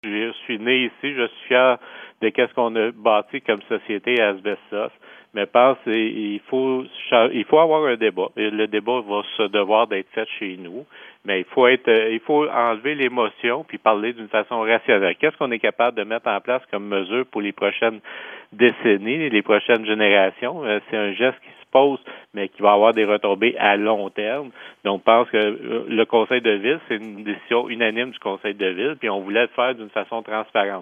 entrevistó a Hugues Grimard